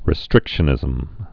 (rĭ-strĭkshə-nĭzəm)